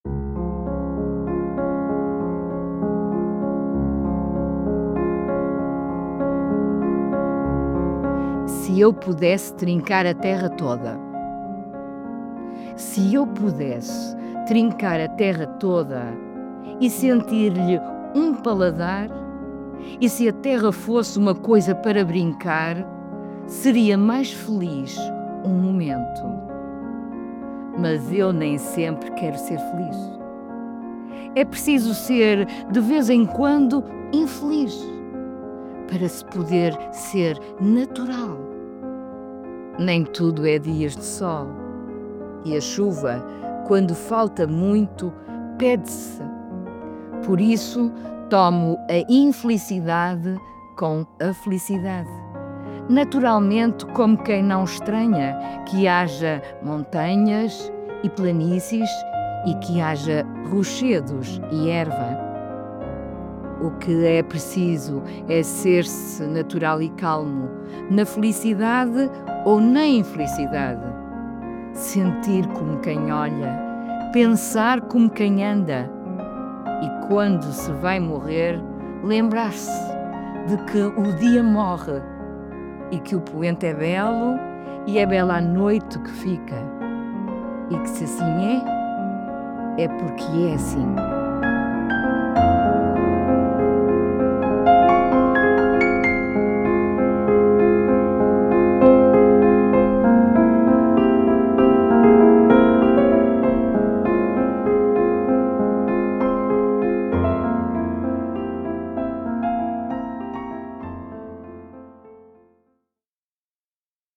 Música: Consolation No. 3 in D Flat Major – S. 172, de Franz Liszt